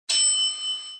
电梯到了.mp3